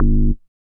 bass hit.wav